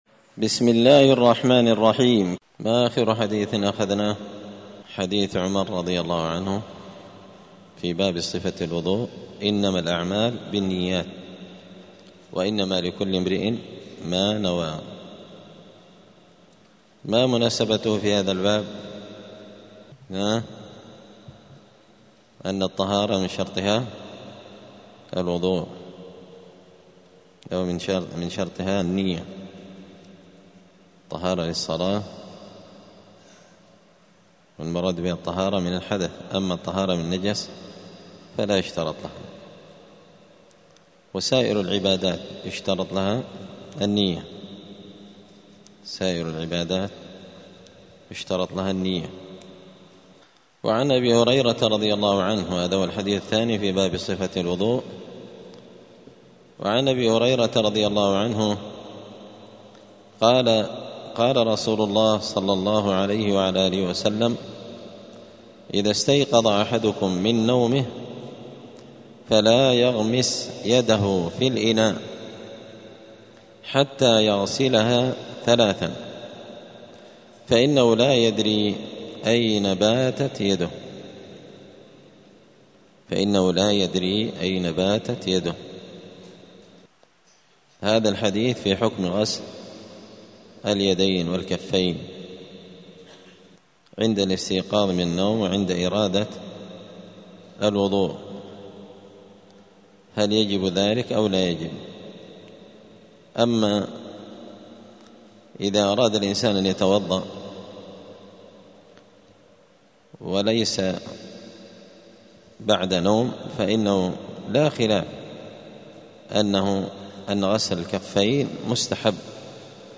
دار الحديث السلفية بمسجد الفرقان بقشن المهرة اليمن
*الدرس الرابع والعشرون [24] {باب صفة الوضوء غسل الكفين بعد الاستيقاظ من النوم…}*